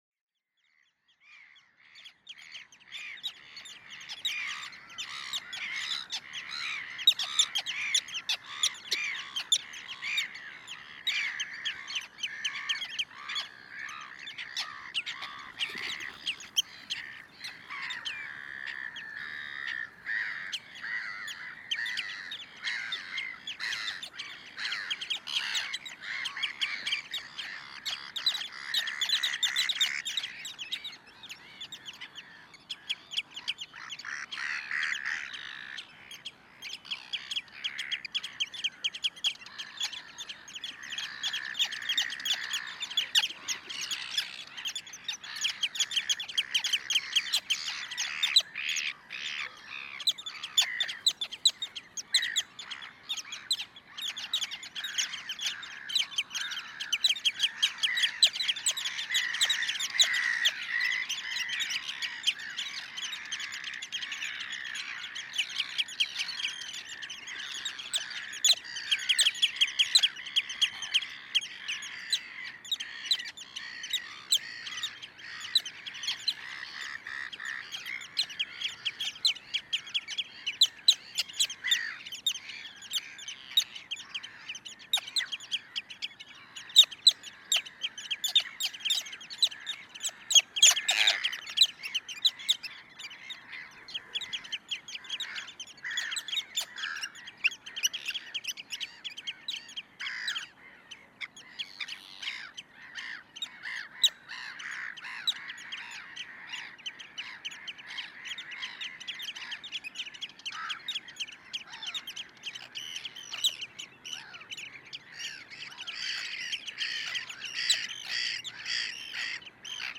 Arctic tern in Önundarfjörður 2012
After midnight I entered an Arctic tern colony to record their sounds. The recording conditions was as good as it gets. The weather was calm, dry but cloudy, and the temperature was about 7 °C. Almost no traffic was in the fjord so most of the background noise was only coming from falling water in the mountains and sea waves at the shore.
So this recording includes sounds from many other bird spaces such as Whimbrel, Black-headed Gull, Black-tailed Godwit, Common Redshank, Oystercatcher, and Golden Plover… and many more The recording contains also human and sheep voices from a nearby farm. Swans, Red-throat Diver, and common Eider at the shore side and a some sound from a Gull colony high in the cliffs all around the fjord. During the recording the Arctic Terns attack many times the furry microphones. Sometimes you may hear their excrement fall to the ground around the microphones, but at this time they never hit or peck the Blimps.
Following recording contains the last 36 minutes so my disturbing visit is not much audible. This is just a nice ordinary summer night in the north west of Iceland.